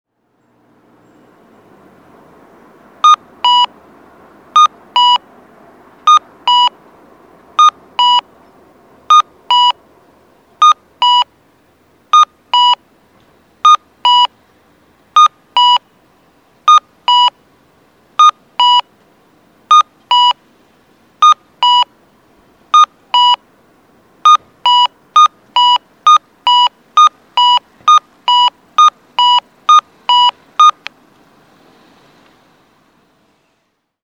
錦町3丁目(大分県大分市)の音響信号を紹介しています。